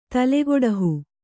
♪ talegoḍahu